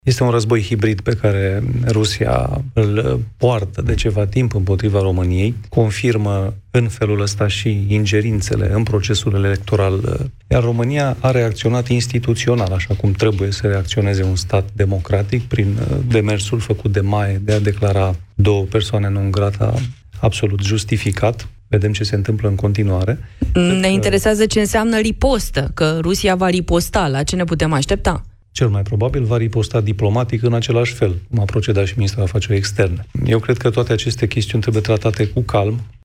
Declararea persona non grata de către MAE a ataşatului militar rus şi a asistentului acestuia este o decizie absolut justificată – spune, la Europa FM, președintele interimar al Senatului, Mircea Abrudean.